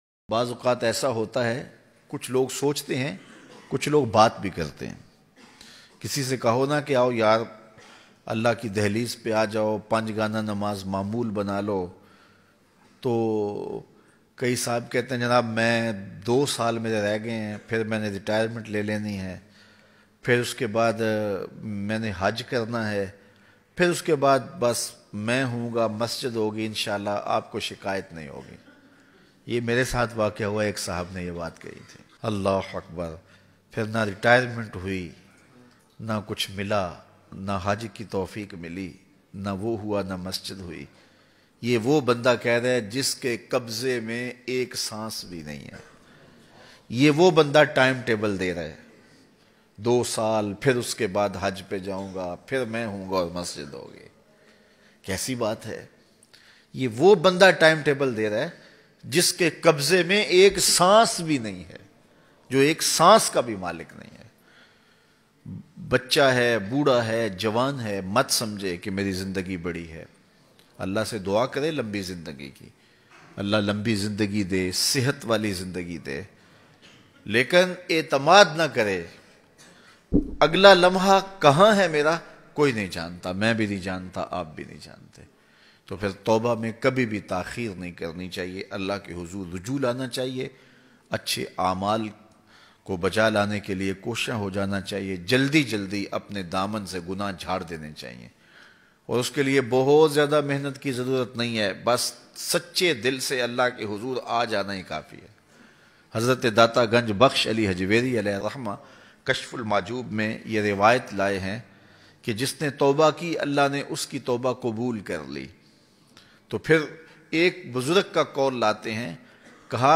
Apna Daman Saaf Karne K Liye Bayan MP3